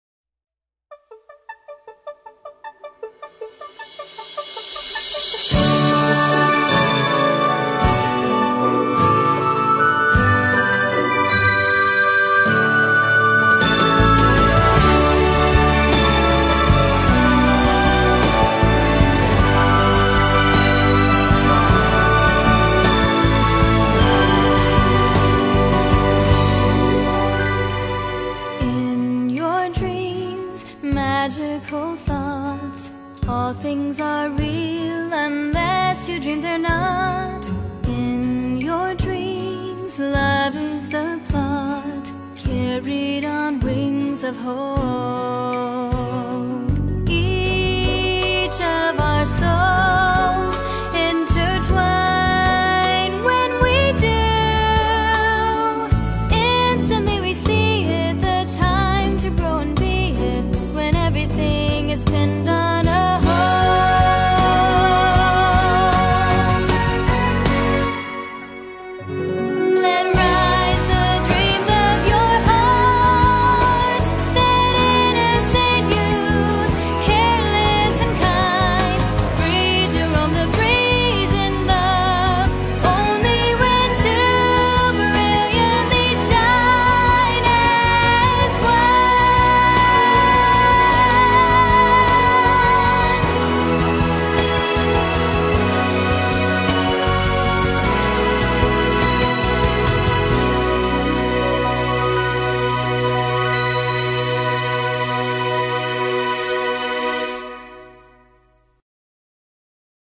BPM90-104
Audio QualityCut From Video